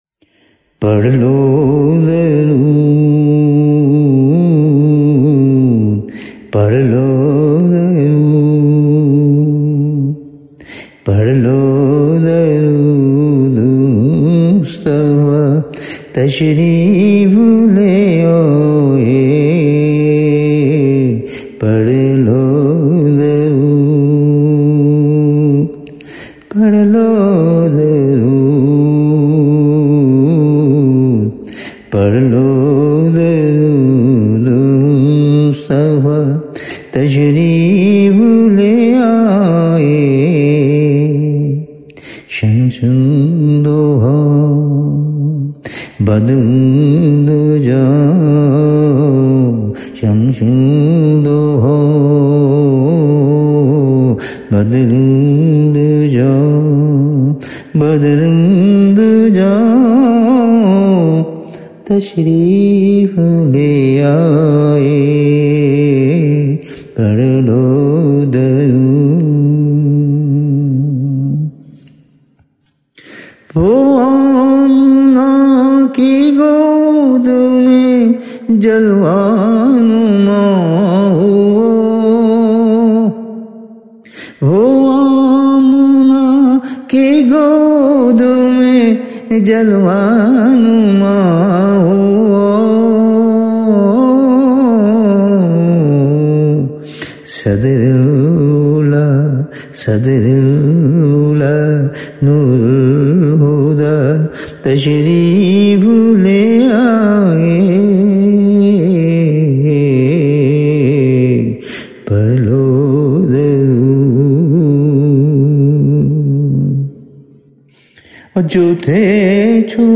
Subhan’Allah, beautifully recited, truly unique and incomparable.